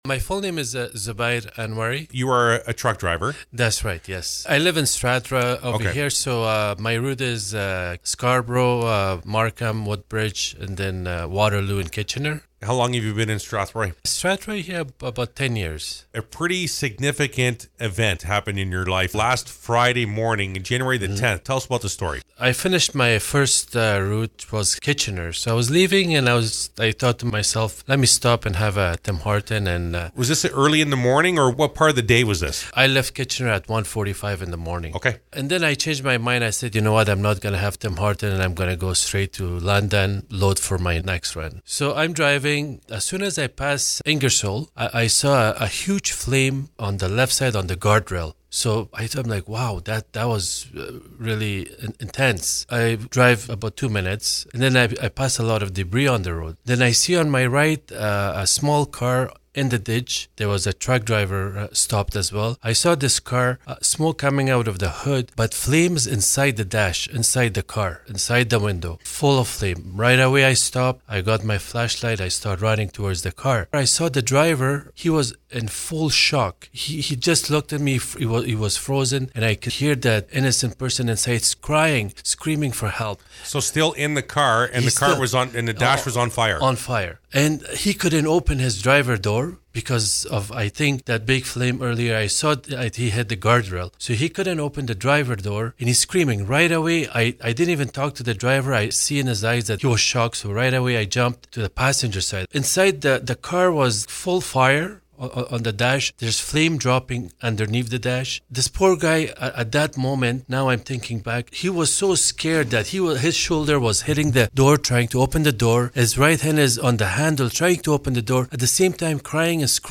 Here is the full interview with the local hero.